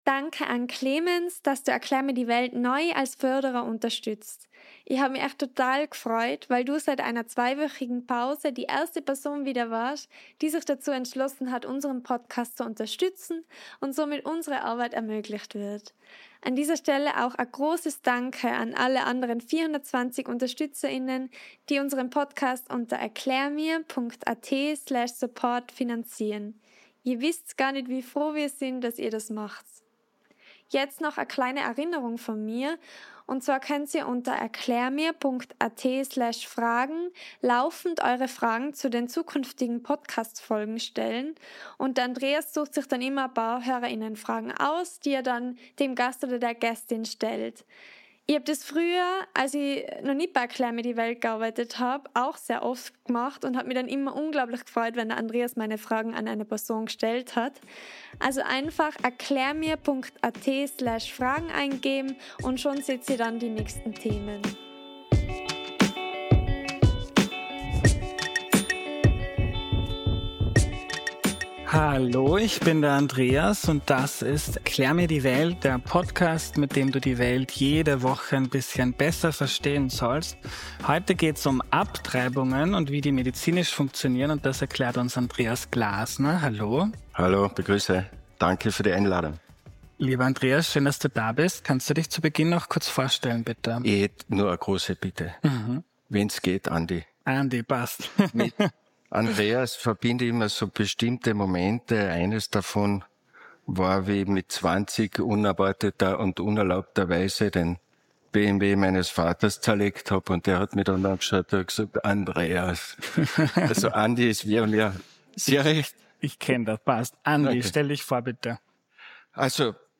Wir haben das heute großteils ausgespart, weil mein Gast ein Arzt ist und kein Ethiker, aber aus meiner Recherche will ich teilen.